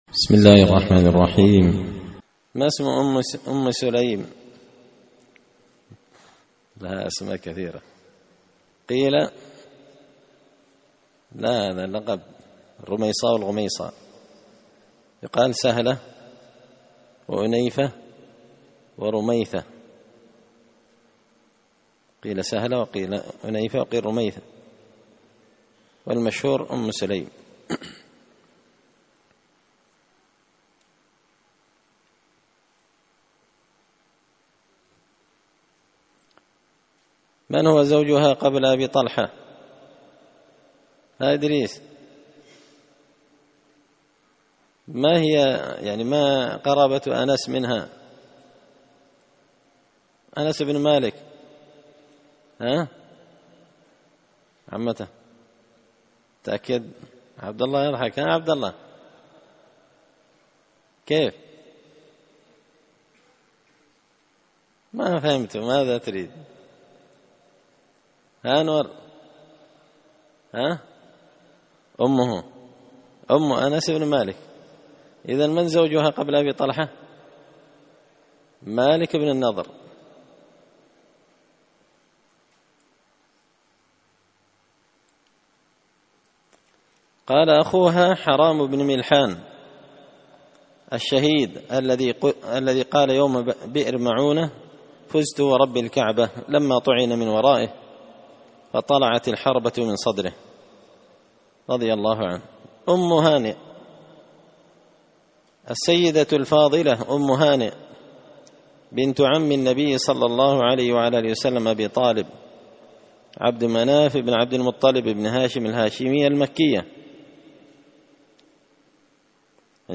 الدرس 101 أم هانئ – قراءة تراجم من تهذيب سير أعلام النبلاء
دار الحديث بمسجد الفرقان ـ قشن ـ المهرة ـ اليمن